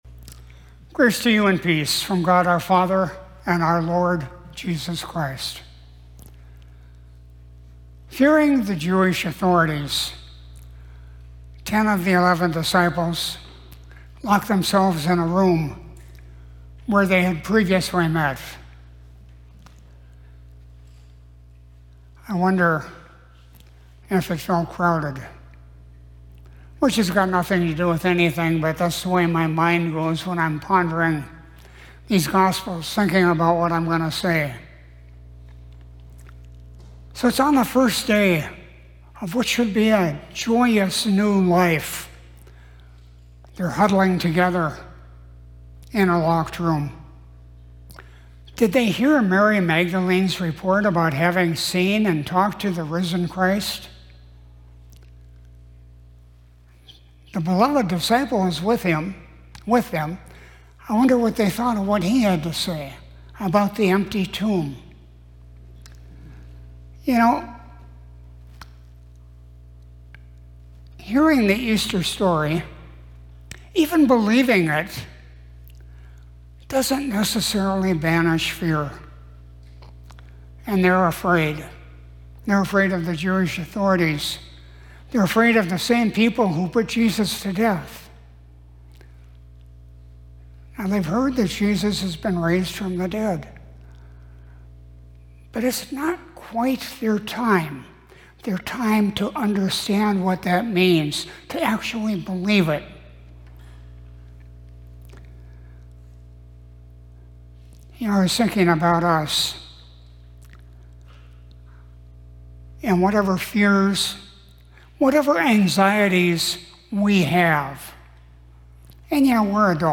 Sermon from Sunday, April 12, 2026